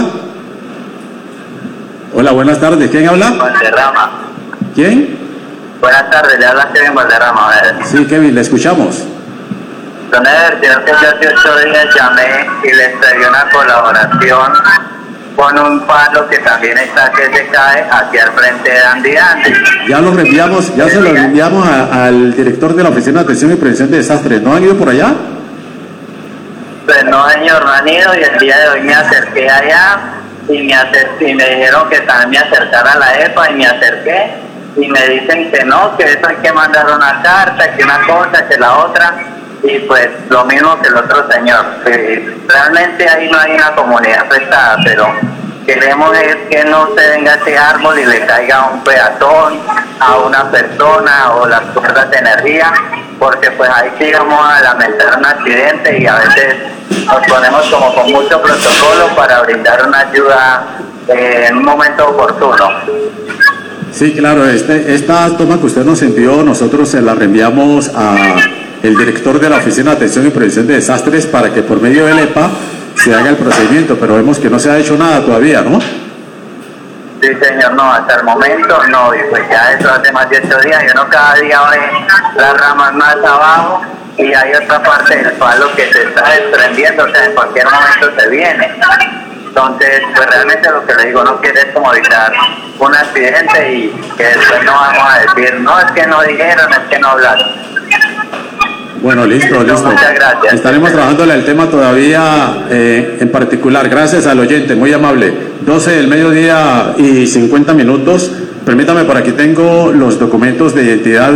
Oyente reporta árbol que se encuentra en riesgo de caerse en centro de Buenaventura
Radio